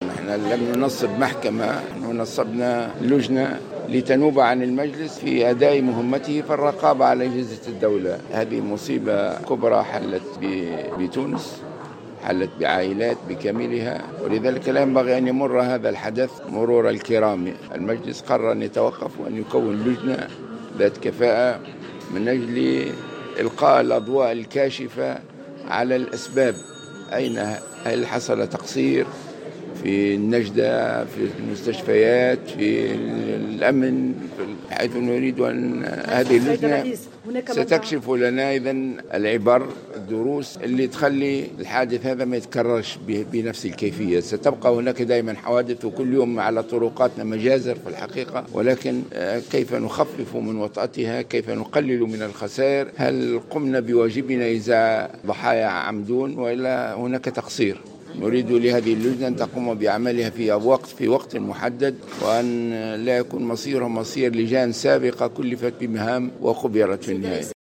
وقال رئيس مجلس نواب الشّعب راشد الغنوشي في تصريح لموفد "الجوهرة اف أم" إنه تم تشكيل هذه اللجنة حتى لا تمر فاجعة عمدون مرور الكرام.